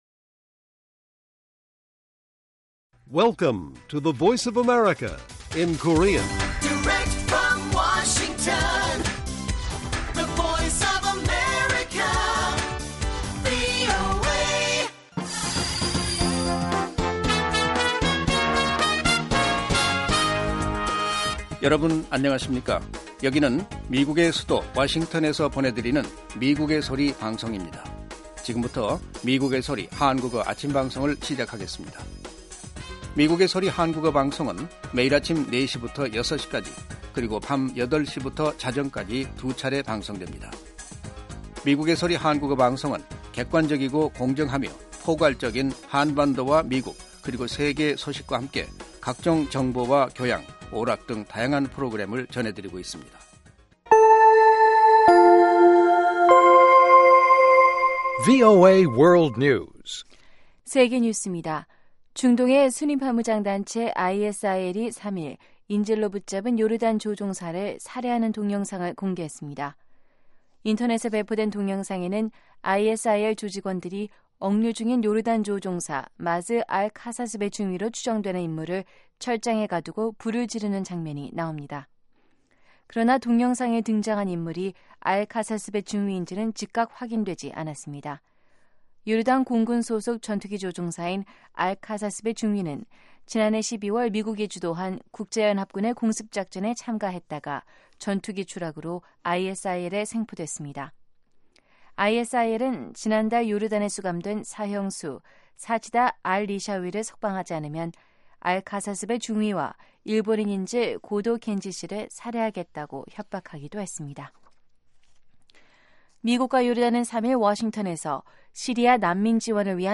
VOA 한국어 방송의 아침 뉴스 프로그램 입니다. 한반도 시간 매일 오전 4시부터 5시까지 방송됩니다.